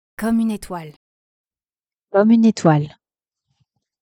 Recorded sound muffled
/uploads/default/original/3X/a/8/a8ae8fdda642f82b52f3951ee3ed73ae9761eb66.mp3 I attached a before/after and you can hear the recorded sound being very different.